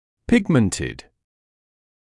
[‘pɪgməntɪd][‘пигмэнтид]пигментированный
pigmented.mp3